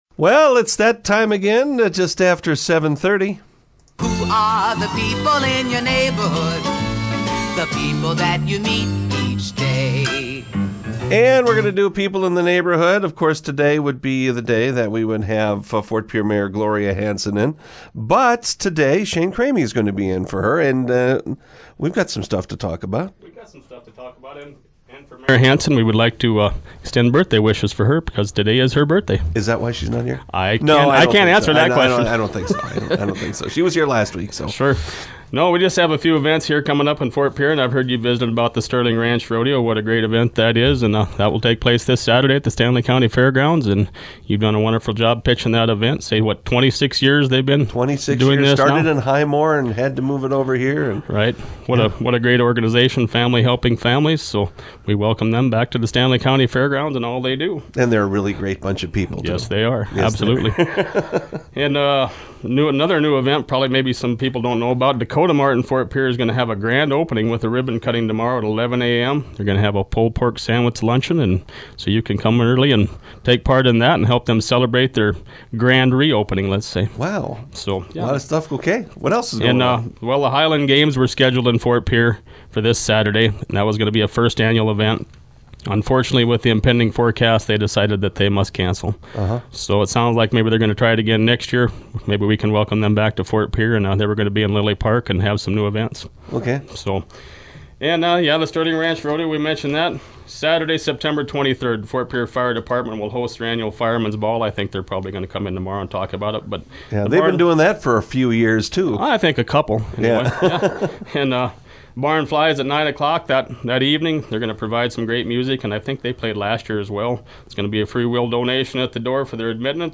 Today’s edition of People In Your Neighborhood on KGFX highlighted the upcoming horse races in Fort Pierre.